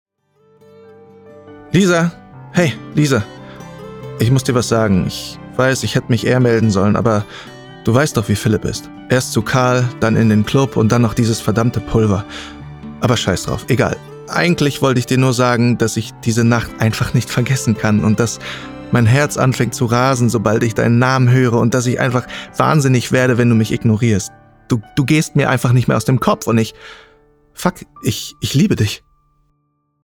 plakativ, markant, sehr variabel
Mittel minus (25-45)
Ruhrgebiet
Audio Drama (Hörspiel)